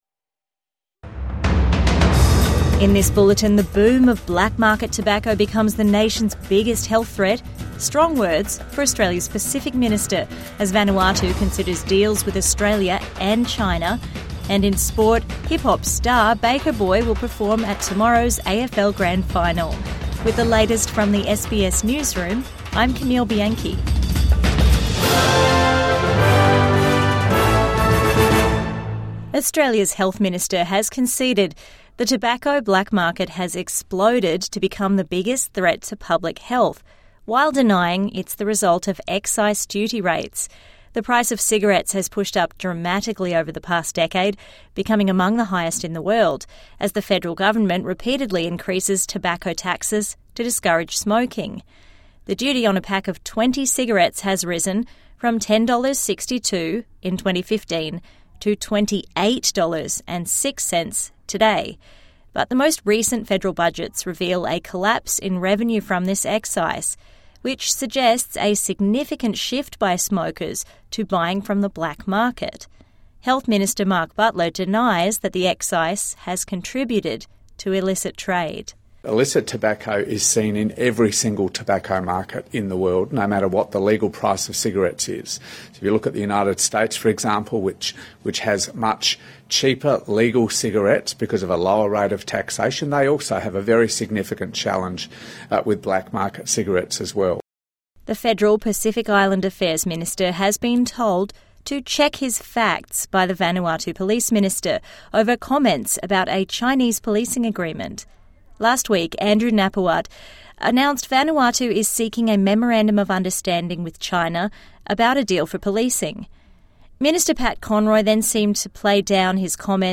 Black market tobacco boom a major threat | Evening News Bulletin 26 September 2025